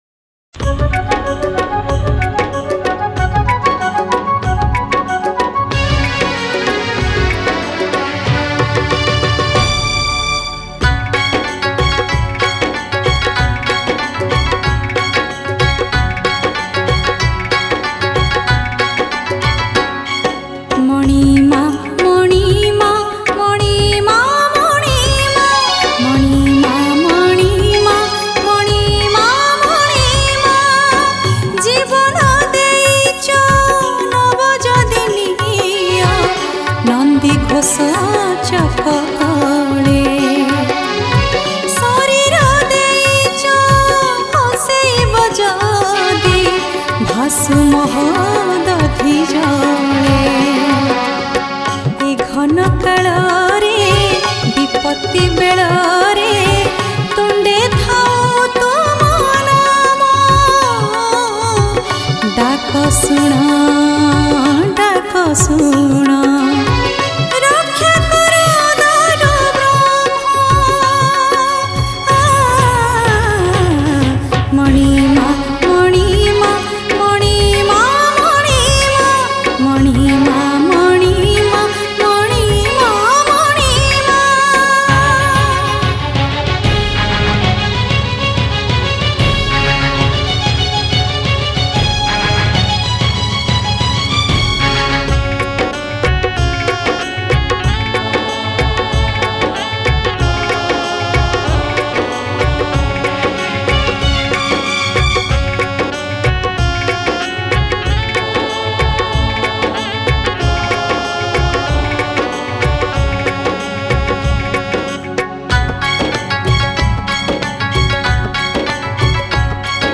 Odia Bhajan
Category: Odia Bhakti Hits Songs